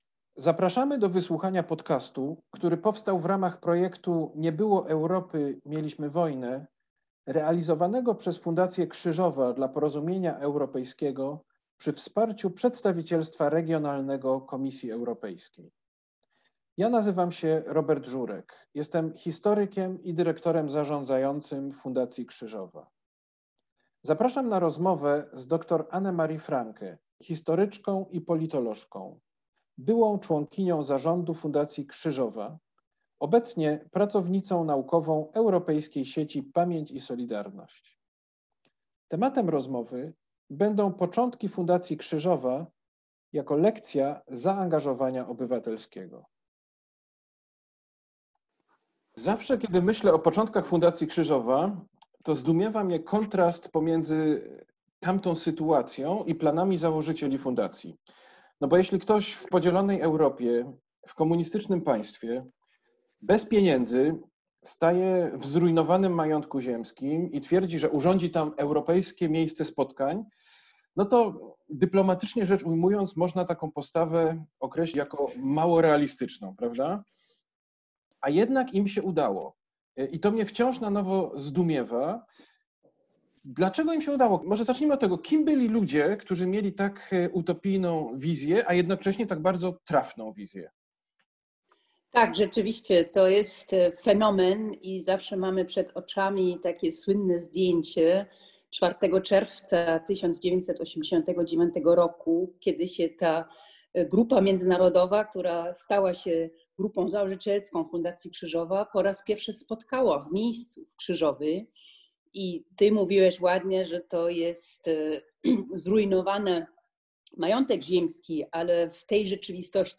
Rozmowa z ekspertem